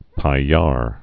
(pī-yär)